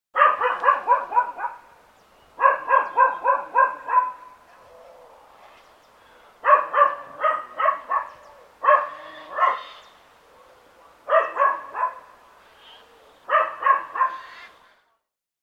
Dog Barking In Yard Sound Effect
Medium-sized dog barking in a neighbor’s yard with natural outdoor ambience in the background.
Dog sounds.
Dog-barking-in-yard-sound-effect.mp3